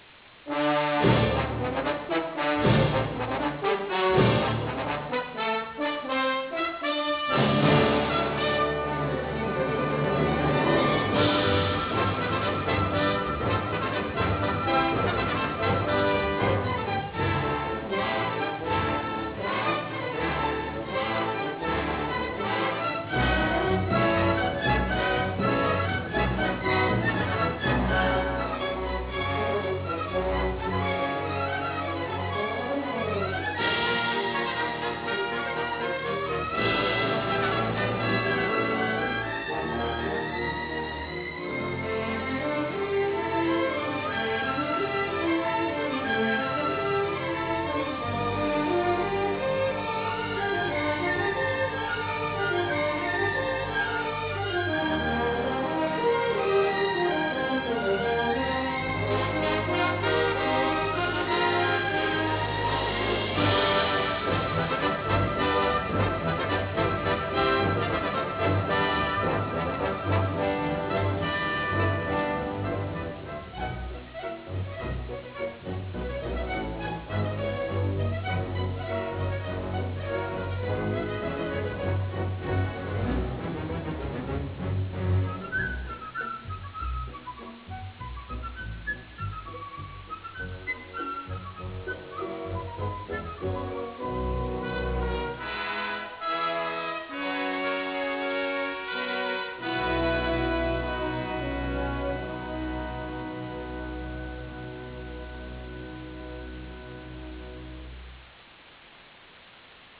vigorosa colonna sonora
Original track music